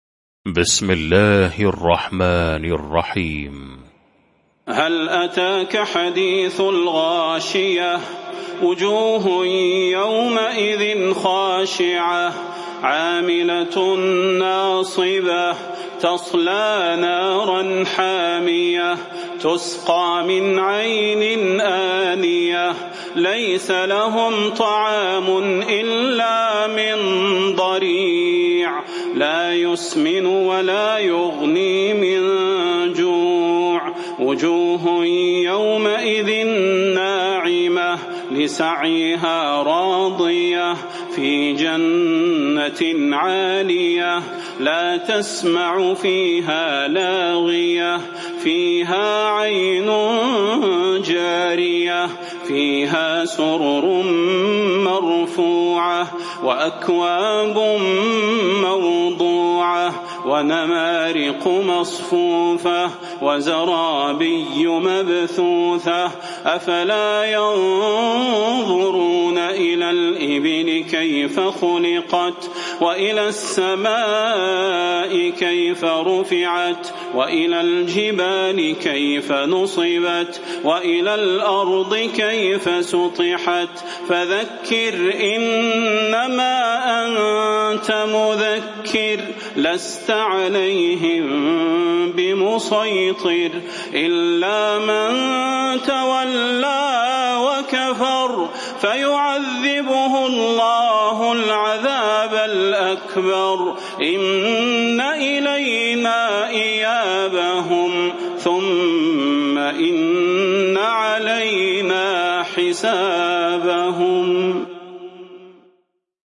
المكان: المسجد النبوي الشيخ: فضيلة الشيخ د. صلاح بن محمد البدير فضيلة الشيخ د. صلاح بن محمد البدير الغاشية